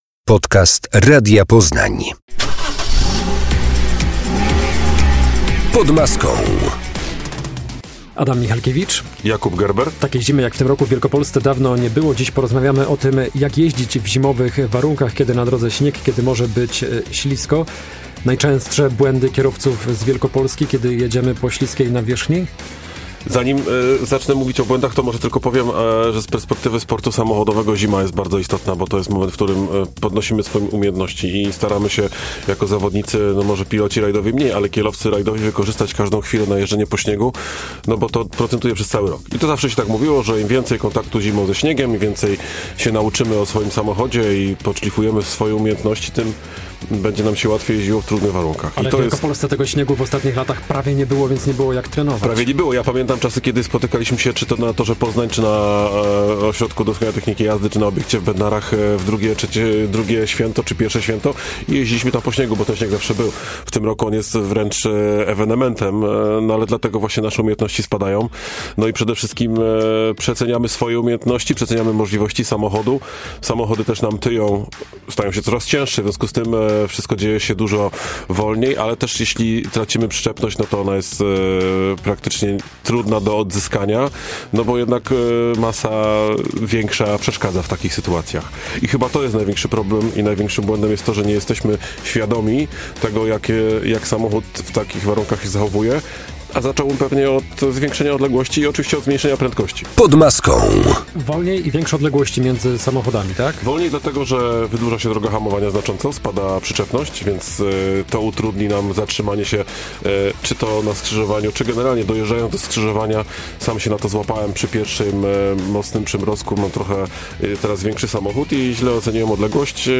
Pod Maską - magazyn motoryzacyjny - 24 stycznia 2026